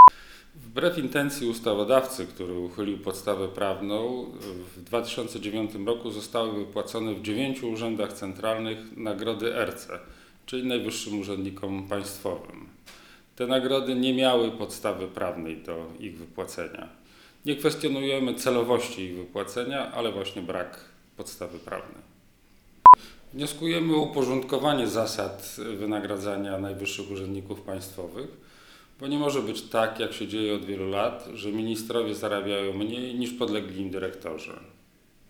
Plik w formacie pdf NIK o budżecie państwa w 2009 r. - szczegółowe informacje (plik PDF) Rozmiar: 1,23 MB Plik w formacie mp3 Dla prasy - wypowiedź prezesa NIK Jacka Jezierskiego - (plik MP3) Rozmiar: 1,35 MB